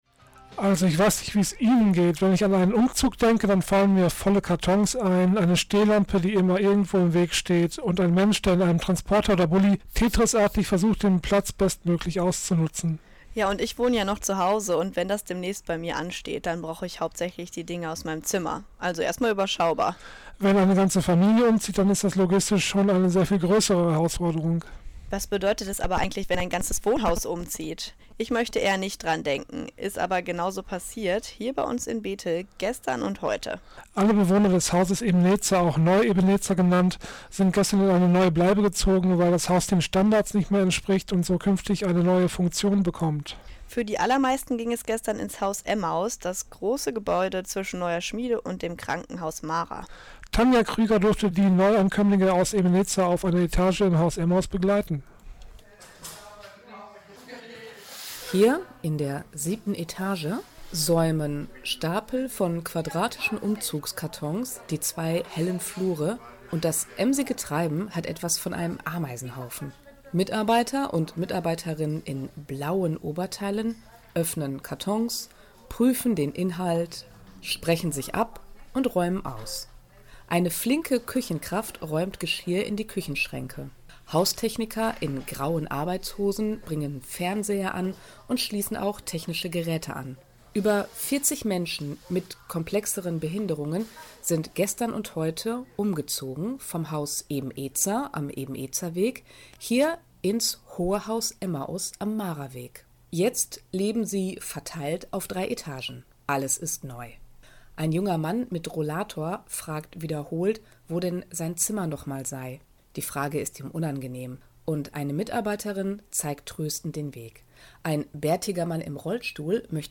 Alle Bewohner des Hauses Ebenezer hier in Bethel sind in diesem Monat umgezogen in das Haus Emmaus am Maraweg. Für über 30 Menschen mussten Kartons gepackt, beschriftet und alles in die neue Bleibe gebracht werden. Wir durften für eine kleine Weile dabei sein: